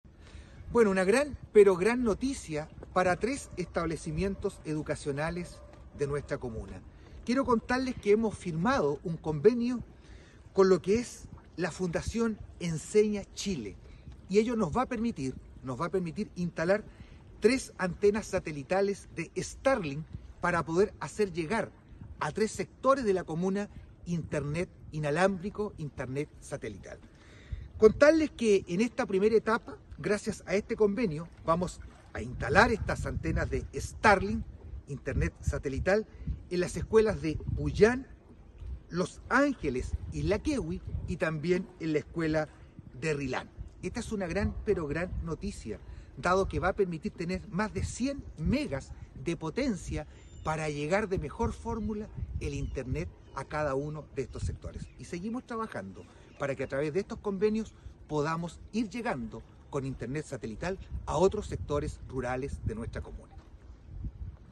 Así lo anunció, el alcalde Juan Eduardo Vera, quien dijo que es una muy buena noticia, ya que en una primera etapa se considera a las escuelas Olinda Bórquez Bórquez de Puyán, Escuela Mirta Oyarzo Vera de Rilán y Escuela Los Ángeles de isla Quehui: